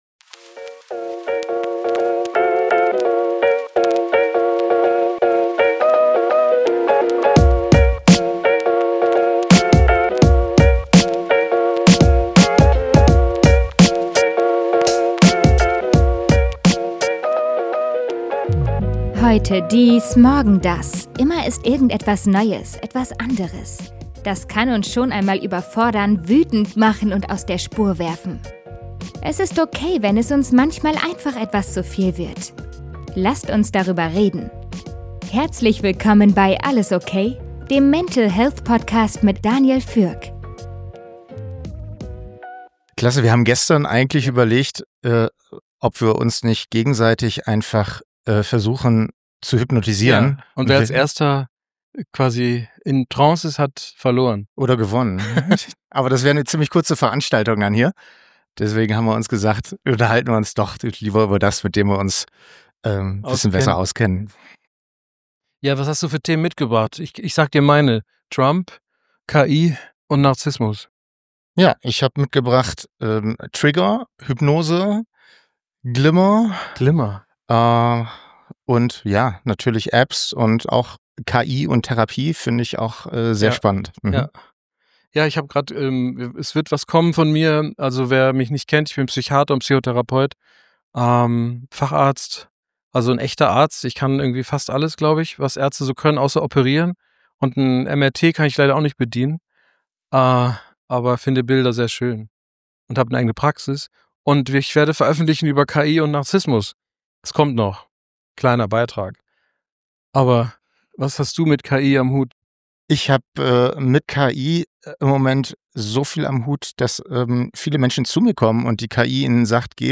Ein Gespräch über Bewusstheit, Manipulation und mentale Selbstbestimmung in einer Zeit, in der das Ich unter Dauerbeschuss steht.